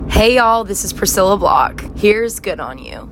LINER Priscilla Block (Good On You) 1